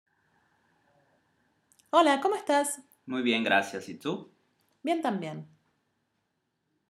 diálogo informal, Ex 3, p3